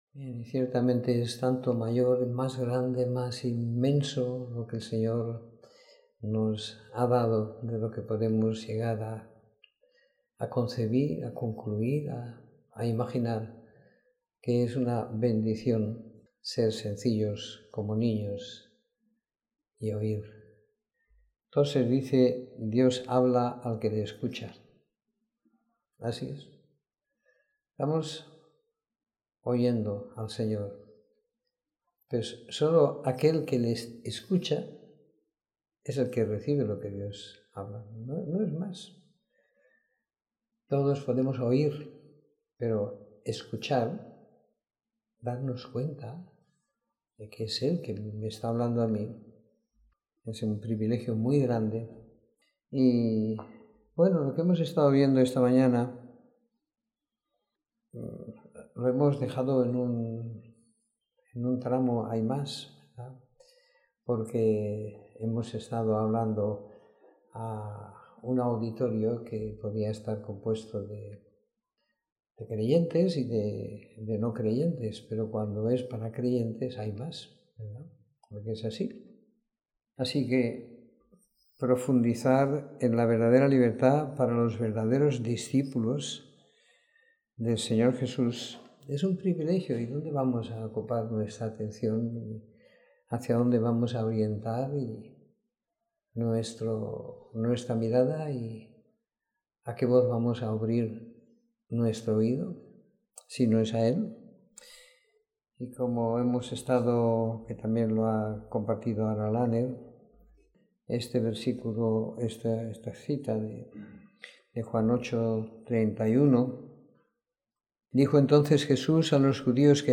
Reunión semanal de compartir la Palabra y la Vida.